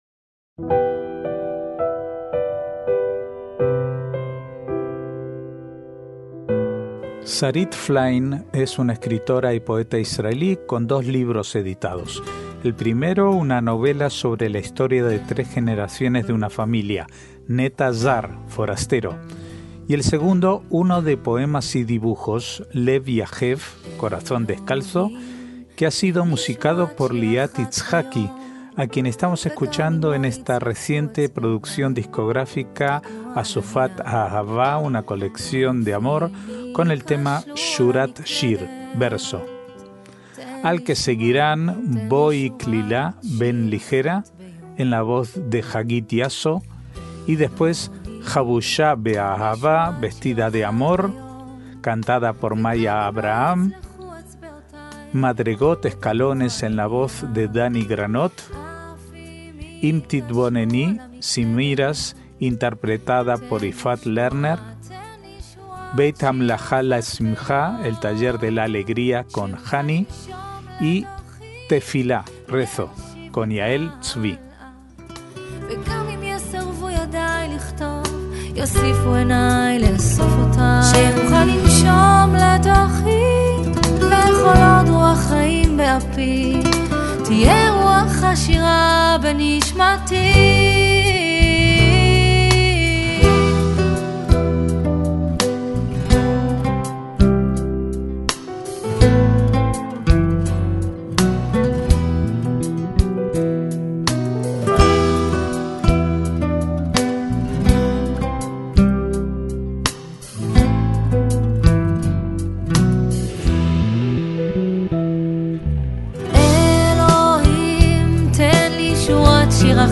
MÚSICA ISRAELÍ